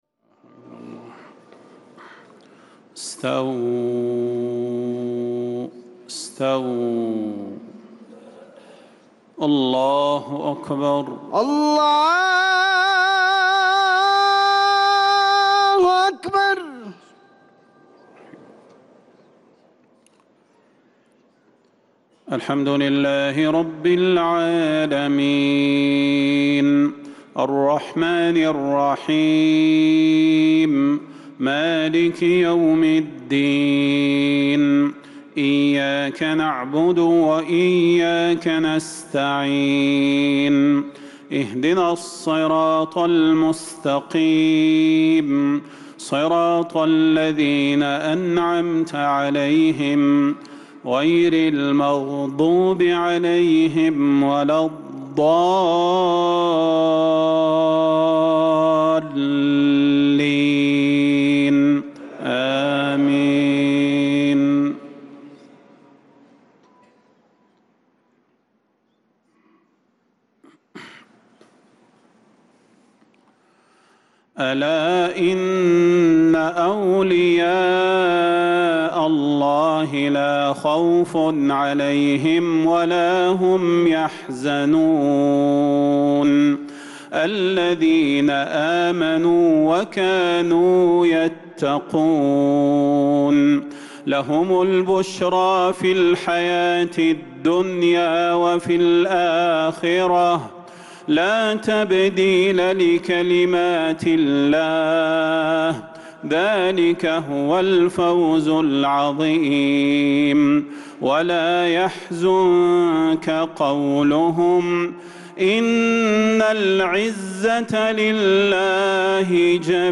صلاة المغرب للقارئ صلاح البدير 20 ذو القعدة 1445 هـ
تِلَاوَات الْحَرَمَيْن .